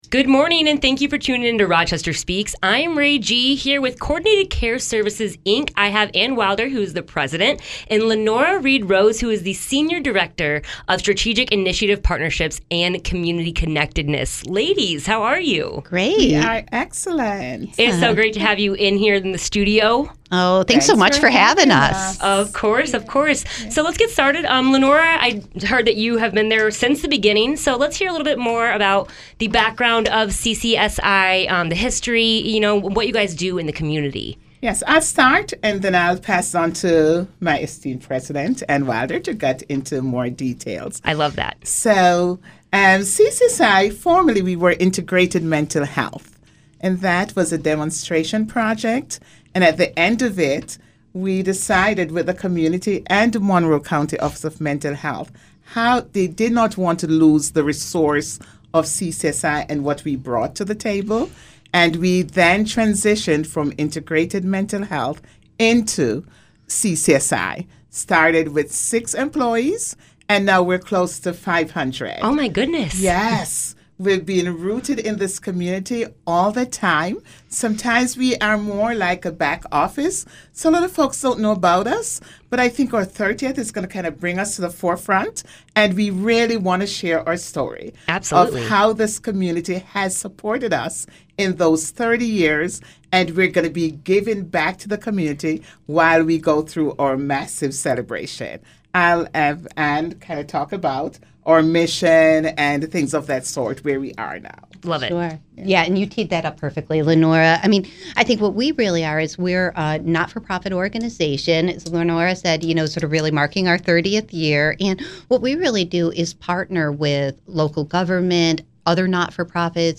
CCSI-interview.mp3